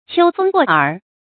注音：ㄑㄧㄡ ㄈㄥ ㄍㄨㄛˋ ㄦˇ
秋風過耳的讀法